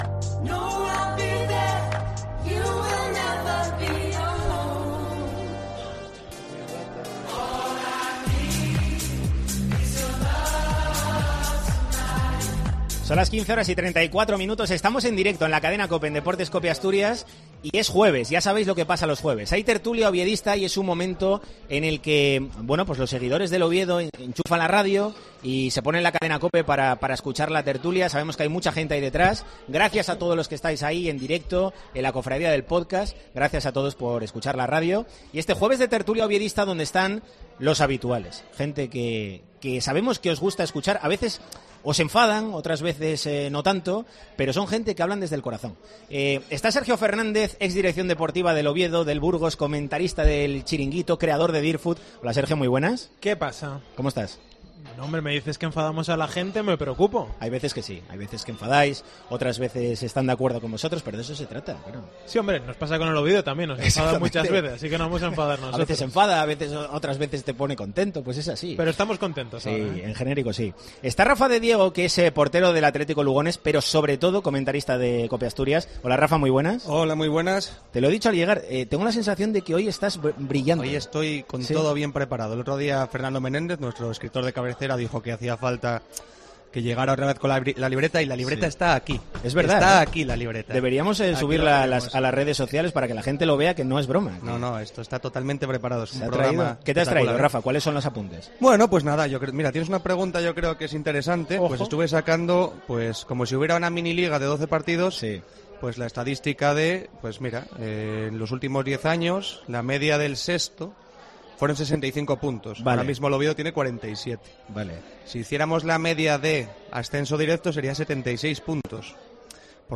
En el capítulo de este jueves de 'La Tertulia del Oviedo' en Deportes COPE Asturias , desde la sidrería La Espita , abordamos el estado de felicidad del oviedismo y cómo puede repercutir en el equipo en este tramo decisivo de campeonato.